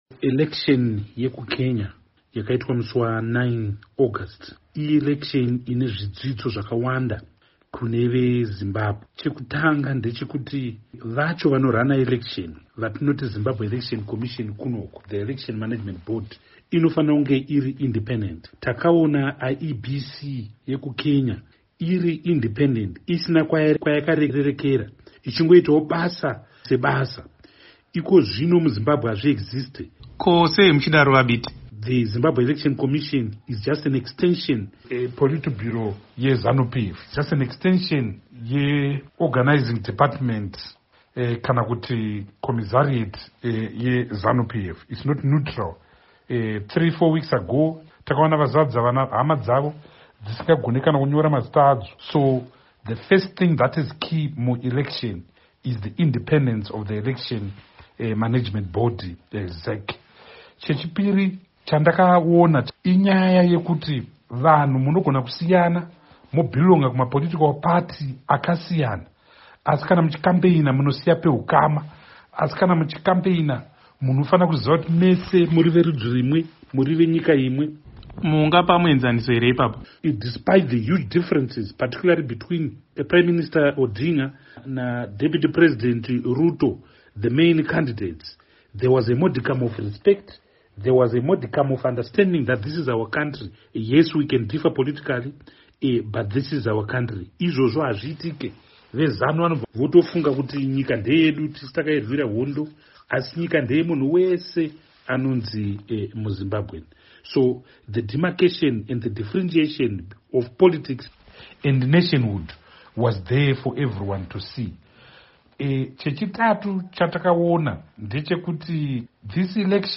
Hurukuro naVaTendai Biti